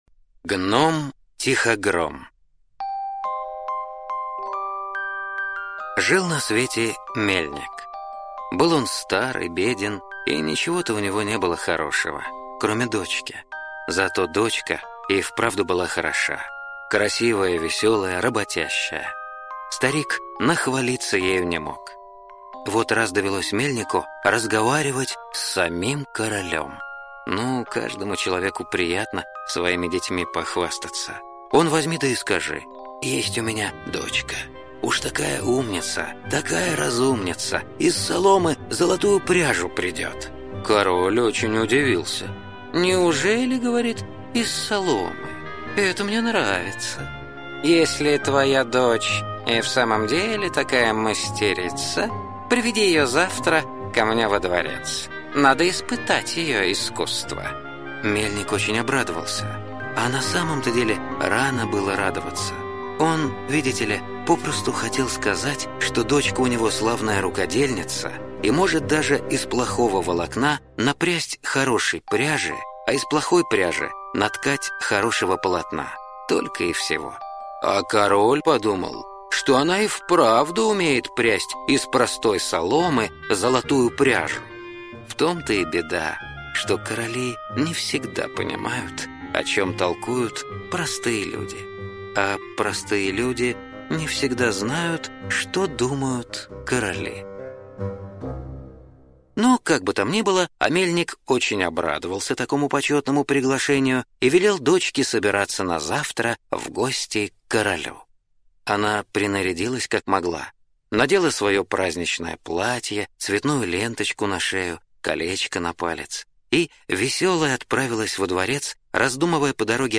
Здесь вы можете слушать онлайн аудиосказку братьев Гримм "Гном-Тихогром". Эта сказка является копией произведения Румпельштильцхен, так как в некоторых изданиях эта сказка называлась по-разному.